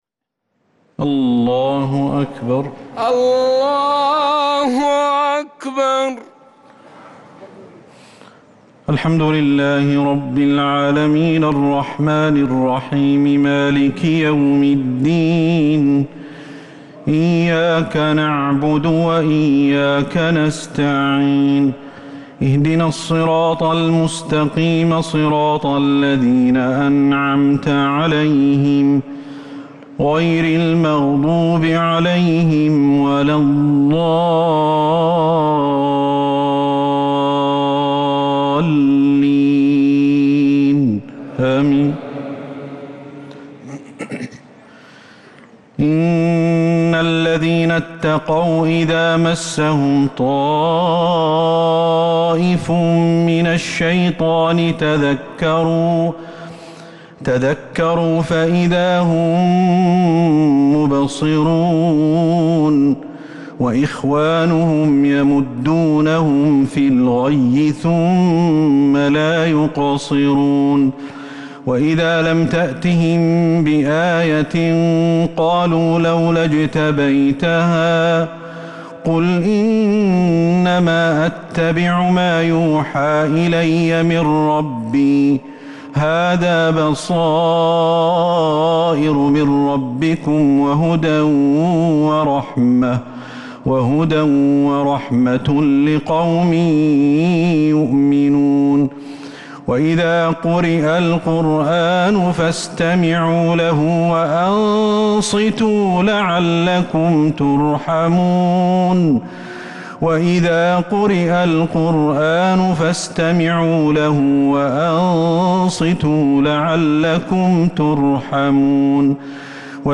صلاة التراويح ليلة 12 رمضان 1443
التسليمتان الأخيرتان صلاة التراويح